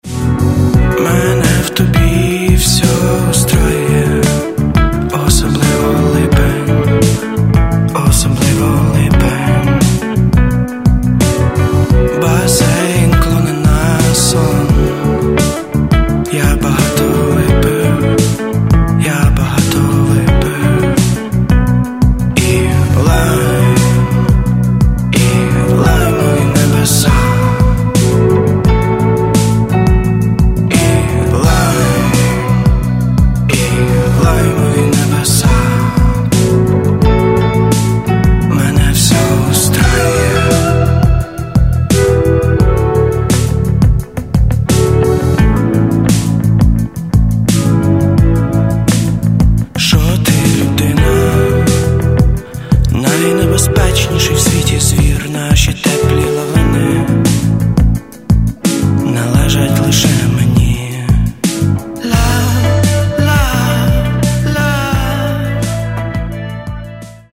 Каталог -> Рок и альтернатива -> Поп рок